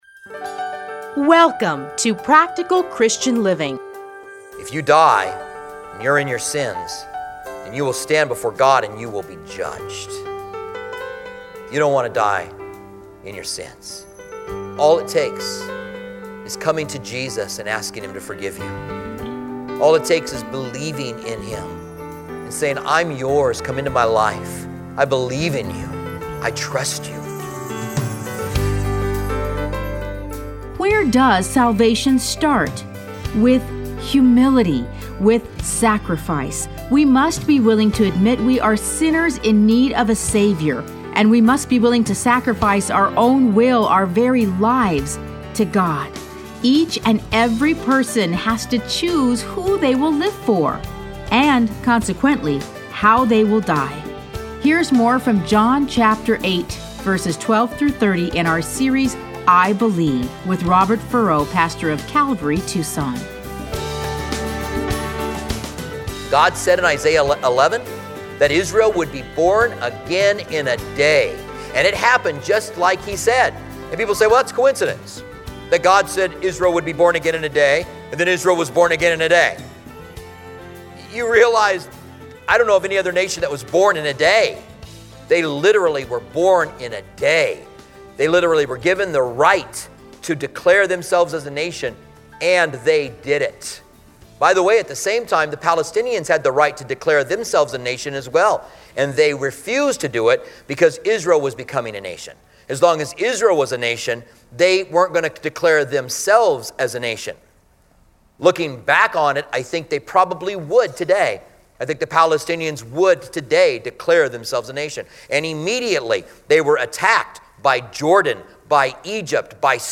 Listen to a teaching from John 8:12-30.